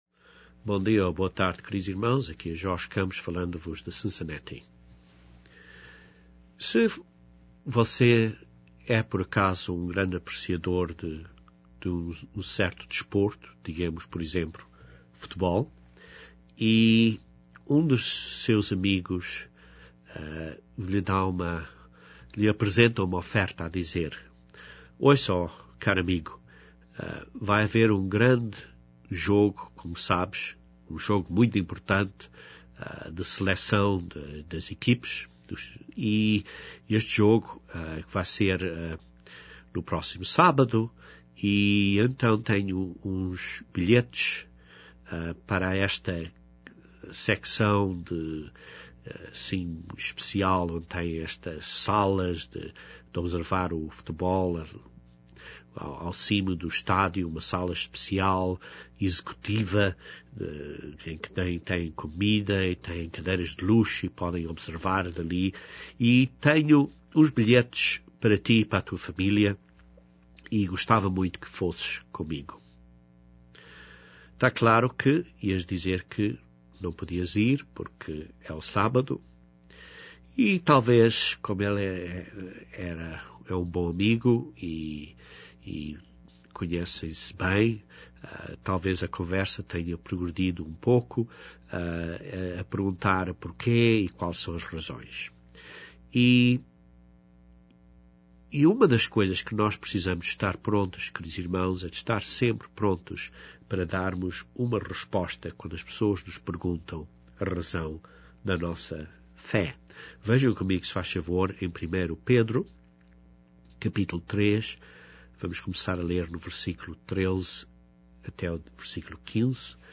As duas primeiras perguntas são respondidas brévemente neste sermão. Depois o estudo bíblico responde à pergunta essencial, que é se Cristo ressuscitou, de fato, num Domingo.